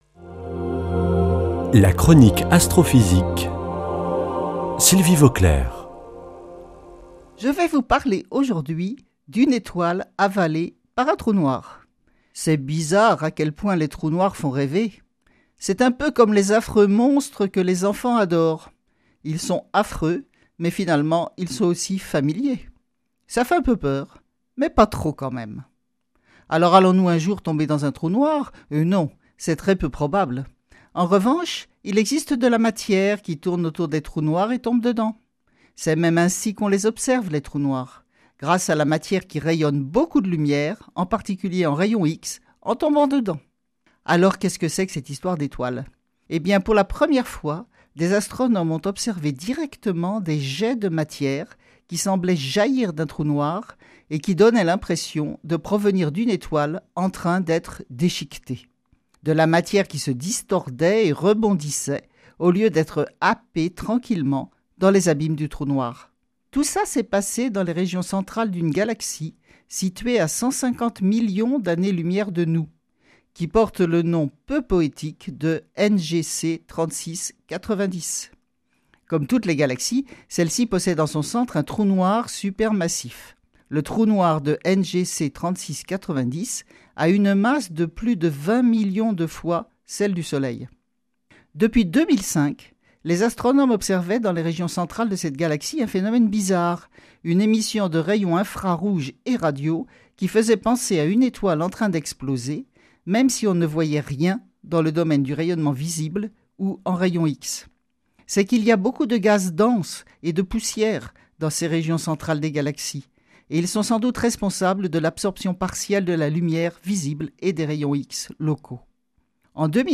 Astrophysicienne